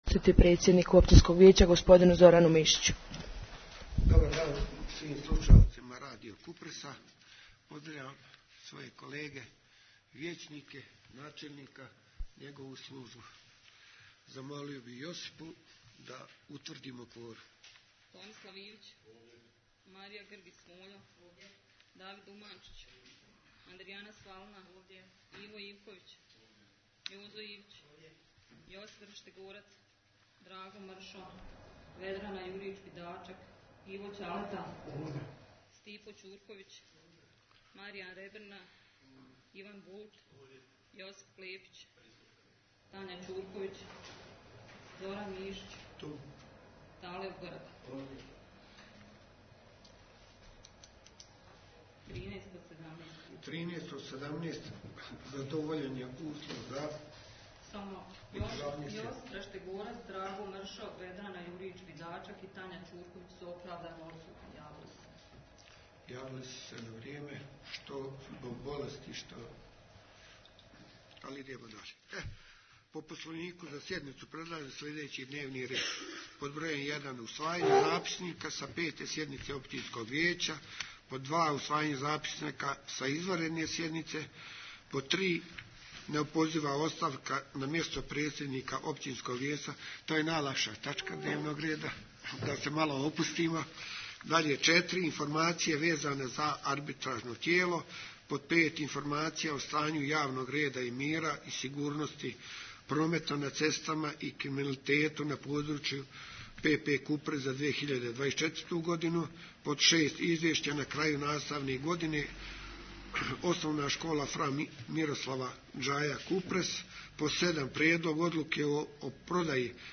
U Kupresu je jučer održana šesta sjednica Općinskog vijeća, na kojoj je sudjelovalo 13 od ukupno 17 vijećnika, čime je ostvaren potrebni kvorum za pravovaljano odlučivanje. Sjednica je protekla u konstruktivnoj raspravi, uz nekoliko važnih odluka i informacija za lokalnu zajednicu.
Šesta_sjednica_OV_Kupres_9_12_20.mp3